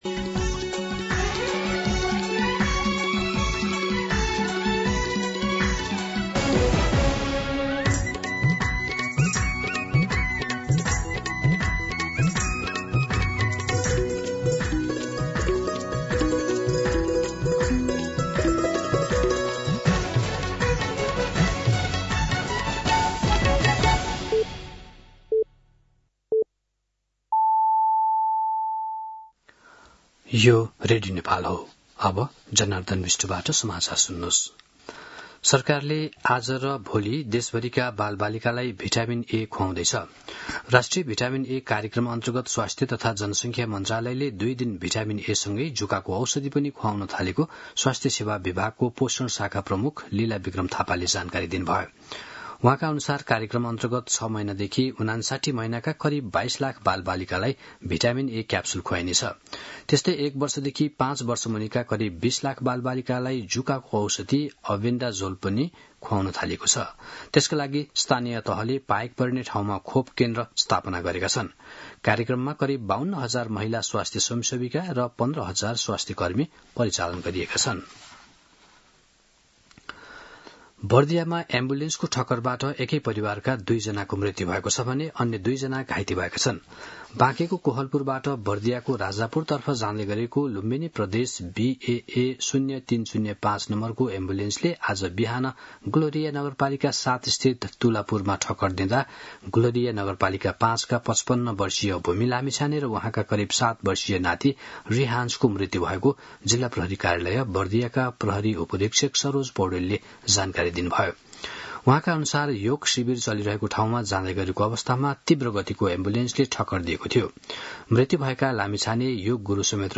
मध्यान्ह १२ बजेको नेपाली समाचार : ६ वैशाख , २०८३